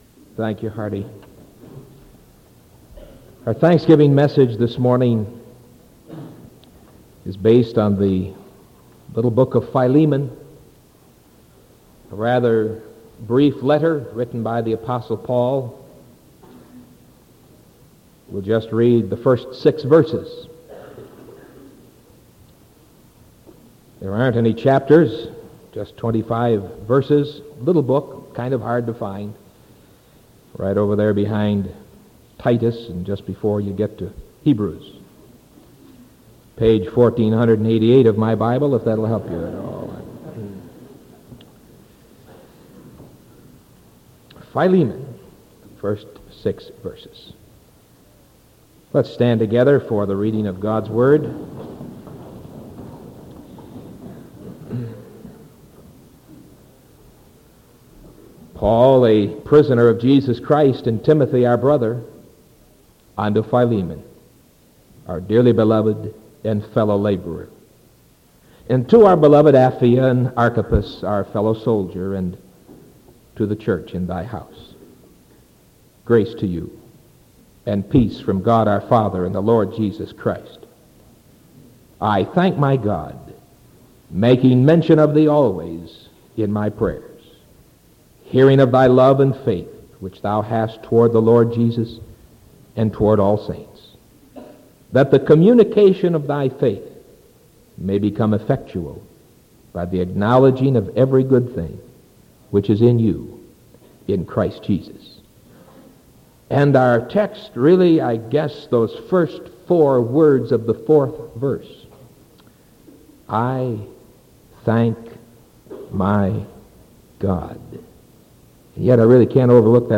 Sermon November 23rd 1975 AM